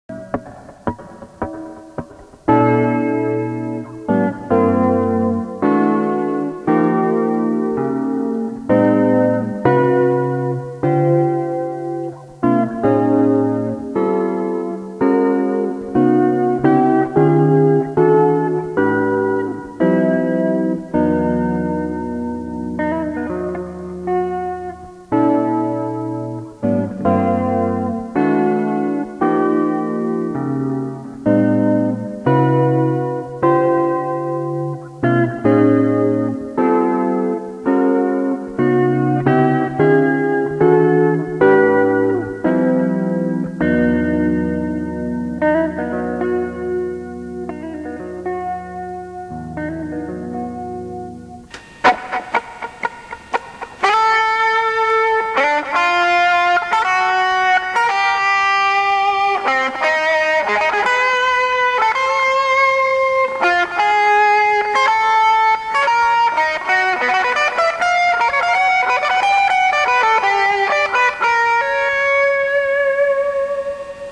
といっても、まださわりだけのように聞こえますね(^^;)。曲ネタをどのようにストックするのかは人によって違いますが、私はとにかく浮んだ内容の目録を記録する感覚でカセットに録音していました。